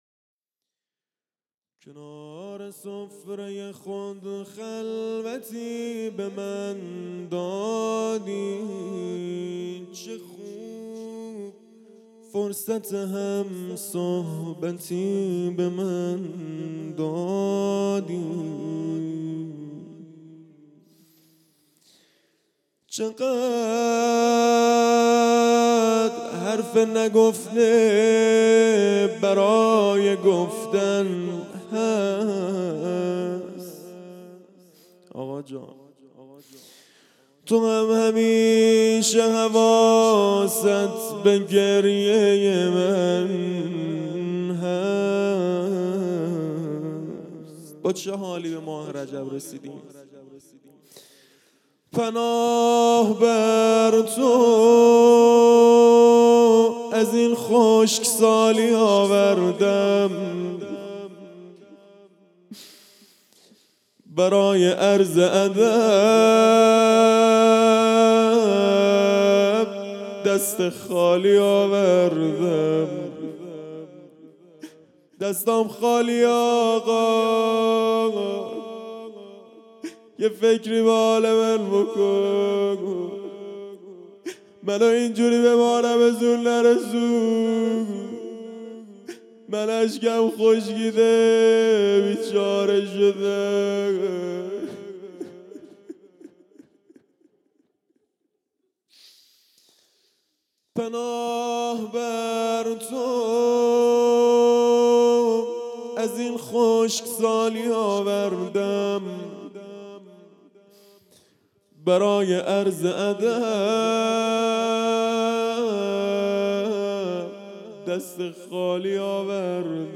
روضه
شهادت امام هادی (ع) 99